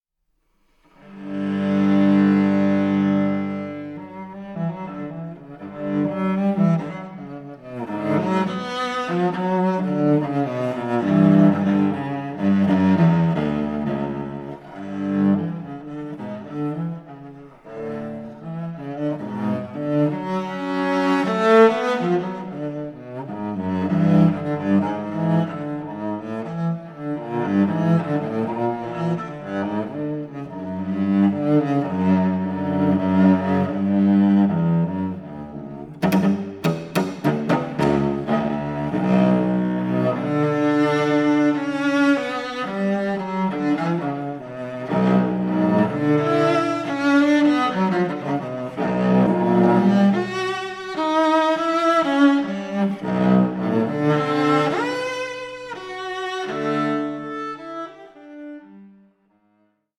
a three-disc compilation of music for solo cello